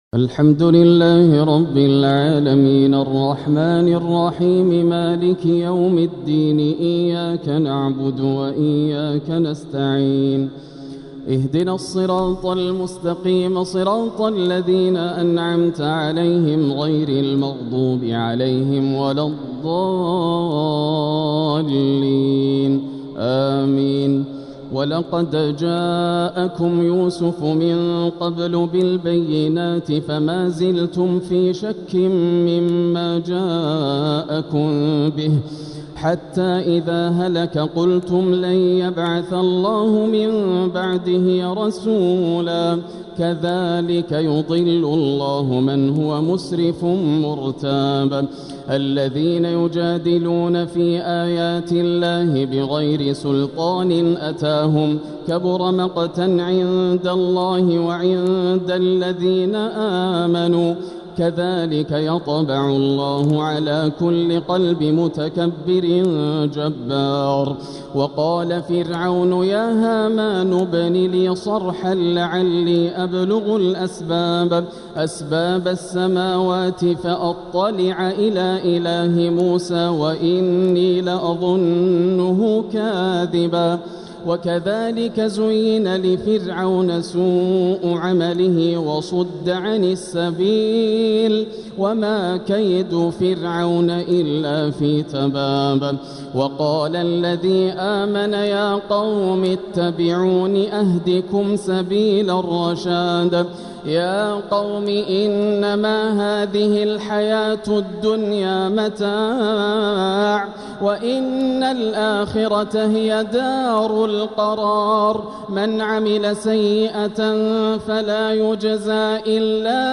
تهجد ليلة 25 رمضان 1446 من سورتي غافر (34-85) وفصلت (1-29) > الليالي الكاملة > رمضان 1446 هـ > التراويح - تلاوات ياسر الدوسري